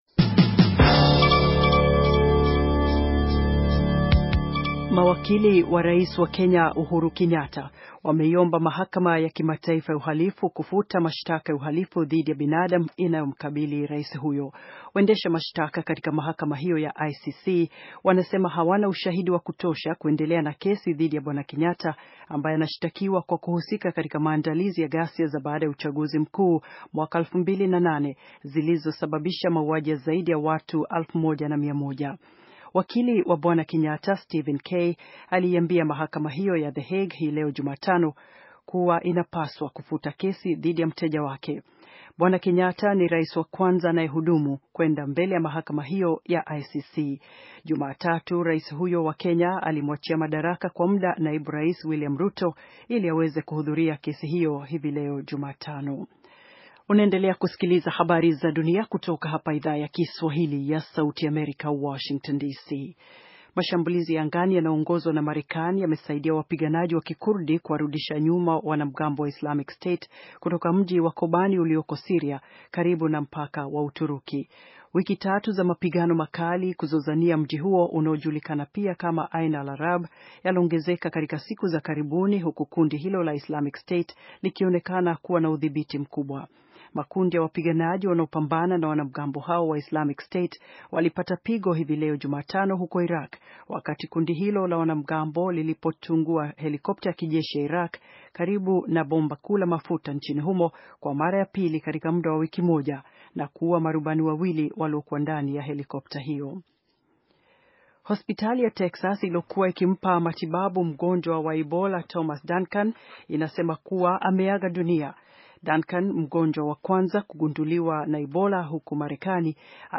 Taarifa ya habari - 4:40